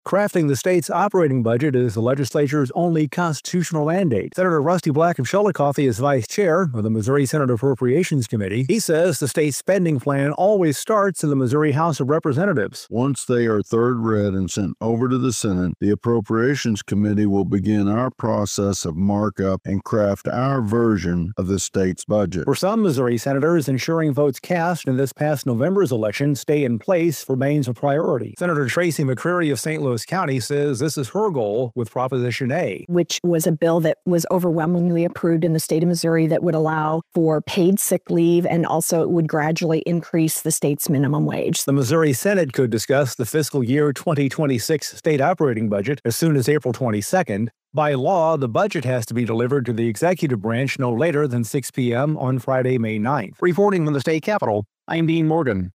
Jefferson City, Mo. (KFMO) - The Missouri Senate moves closer to presenting its version of the state spending plan for the next fiscal year.